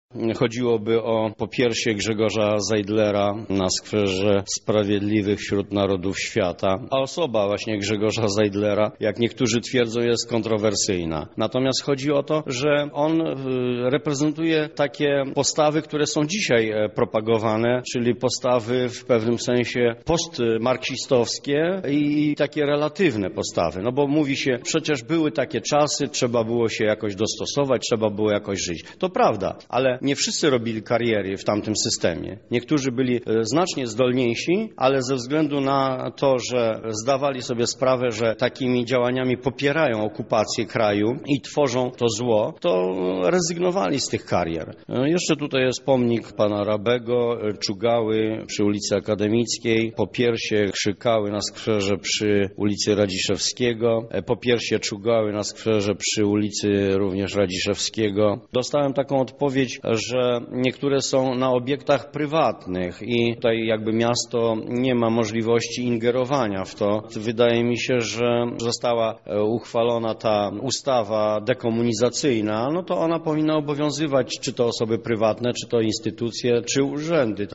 –tłumaczy polityk Prawa i Sprawiedliwości